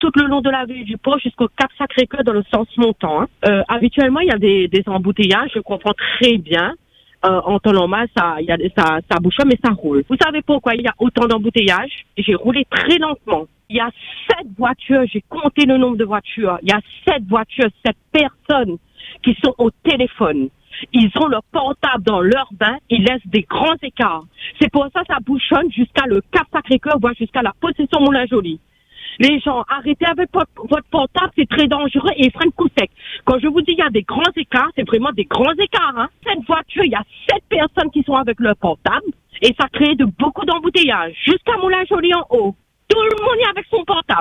Mais pour cette auditrice, le problème est ailleurs.
Un constat qui la met hors d’elle.